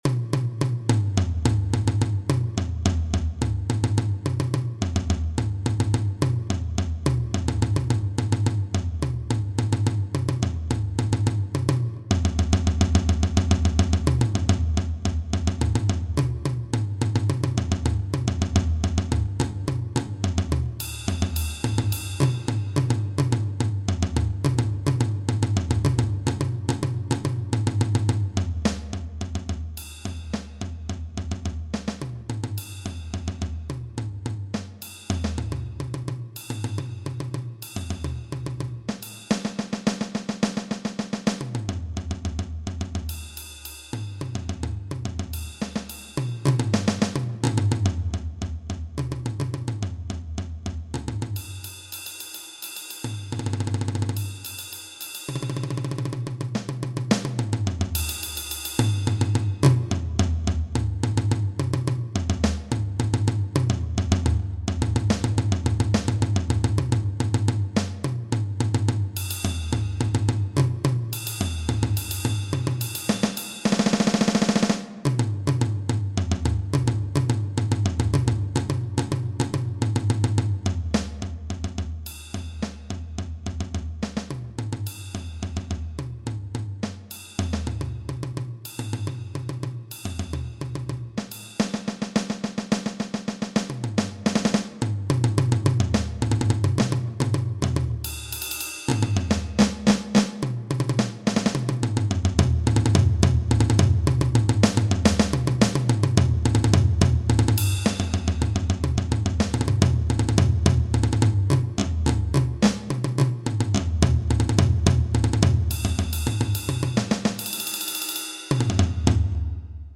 Drum kit
percussion solo